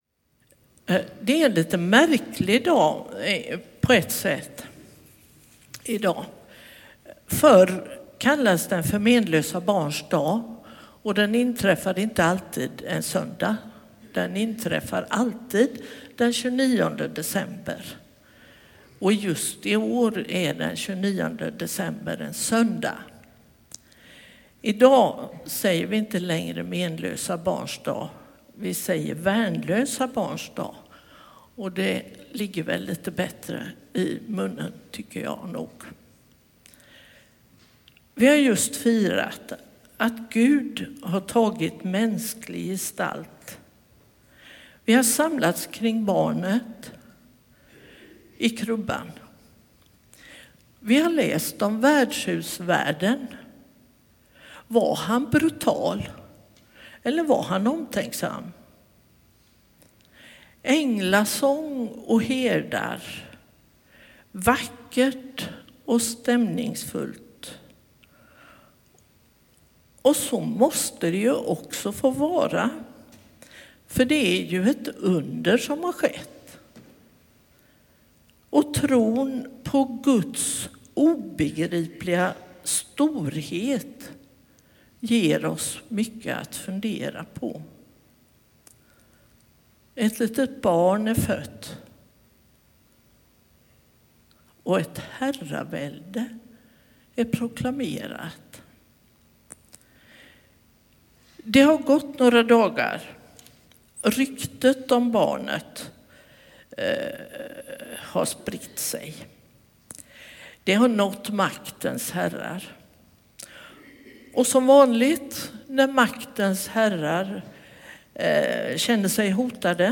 1 Predikan, 2024-12-29 6:17